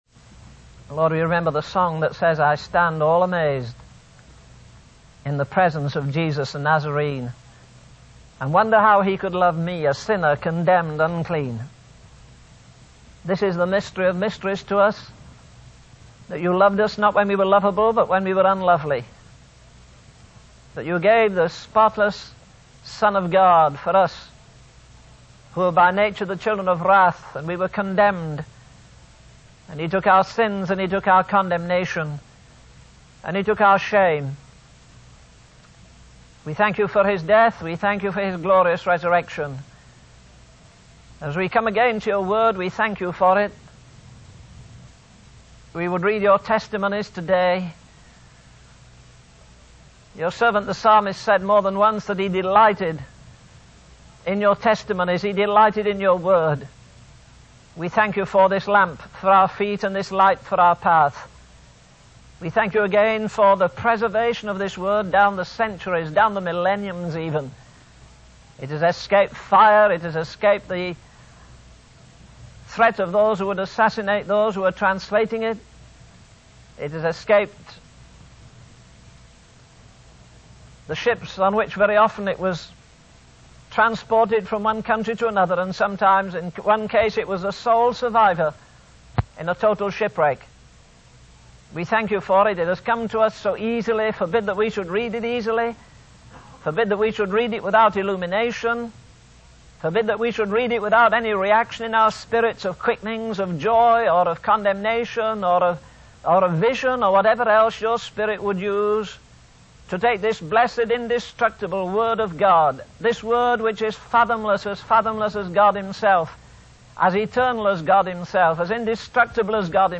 In this sermon, the speaker reflects on the chapter of Isaiah and describes it as a tree full of blossoms and fruit.